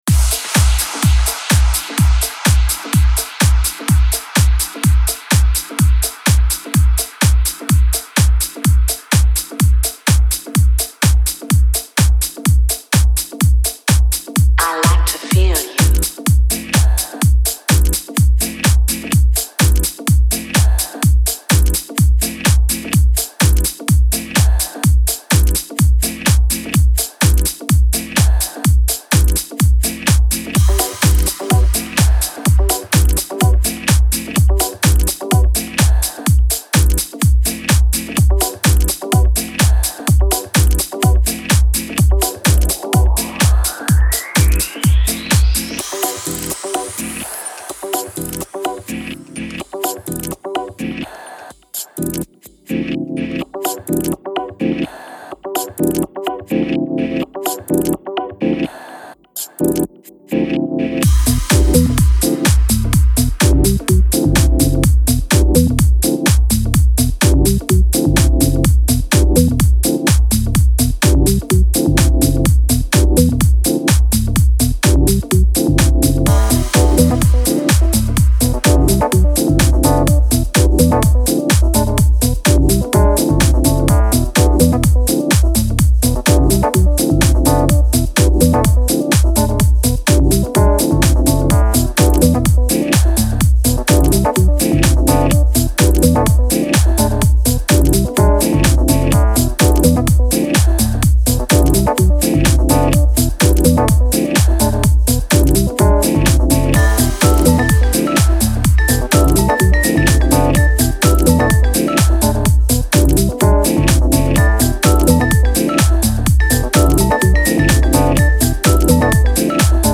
Genre: Deep House.